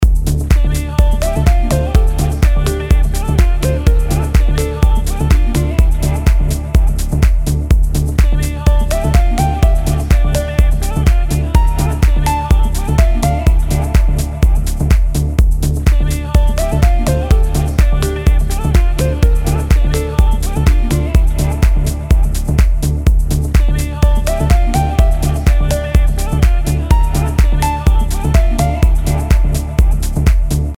Сделал пример - объединил два басовых паттерна в один. Первые 4 такта один паттерн, следующие 4 такта - другой, далее вместе. Пример отфильтрован - срез от 200 Гц. Басовые партии - VST реплика Minimoog. Tech House Bass Template 1-1 Low-Pass 201 Hz